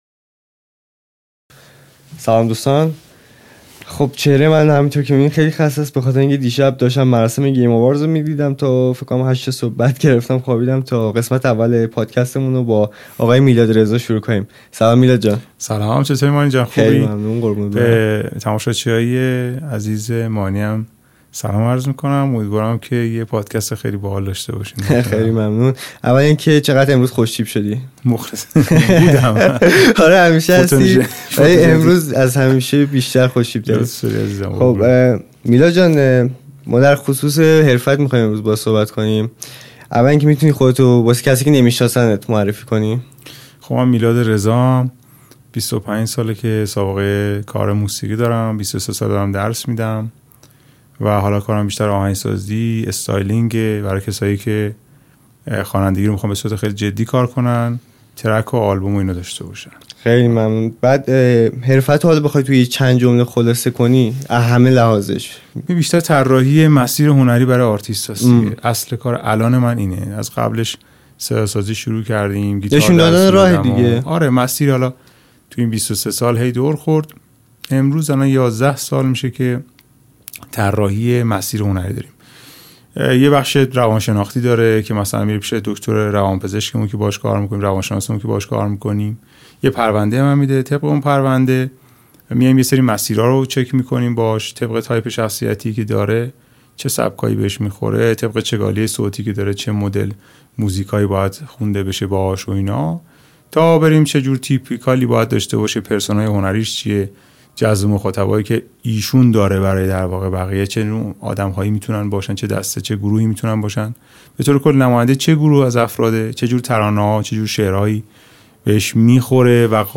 این گفت‌وگو یک کلاس فشرده و عملی است.